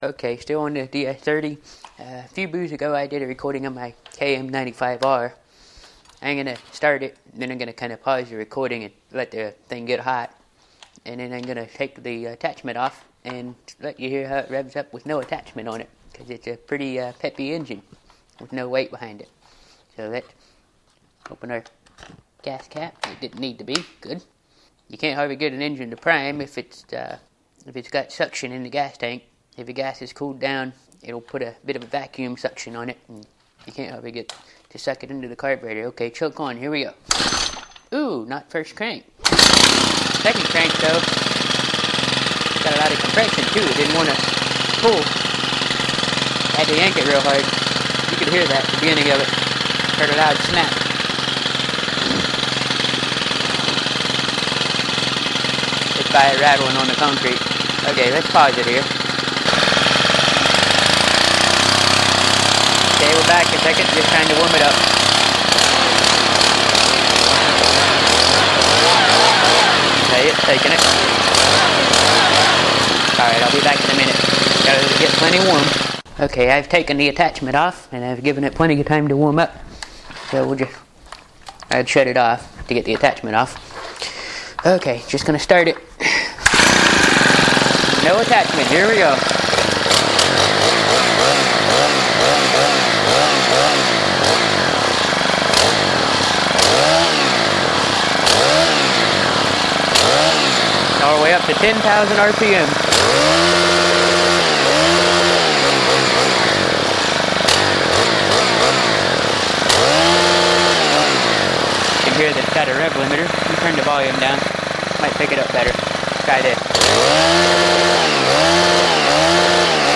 KM95R Stihl Kombi Motor again this time with no attachment
Same Stihl Motor I booed about a few boos ago, the difference is it doesn't have the leaf blower attachment on it, it has none. The engine in this case is very peppy. You get to hear it rev all the way up to 10000 RPM and let's see if I can play some music with the throttle response.